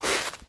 snow_footstep.wav